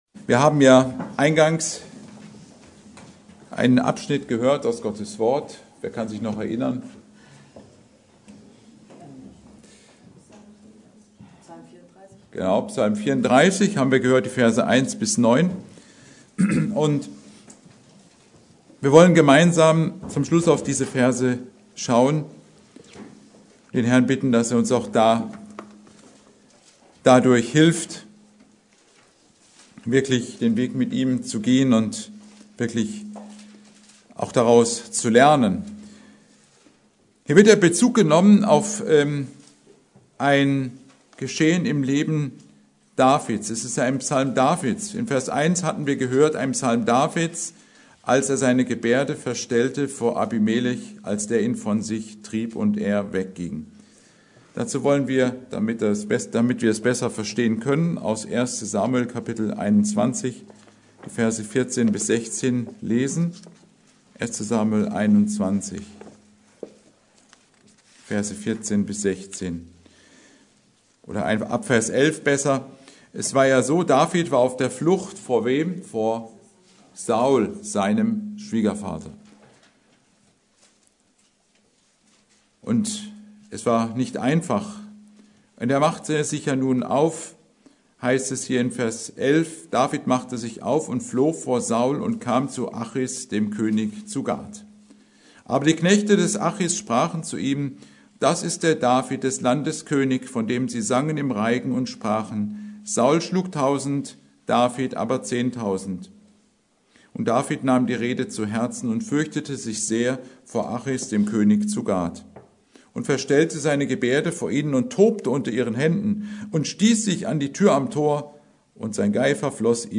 Predigt: Psalm 34,1-9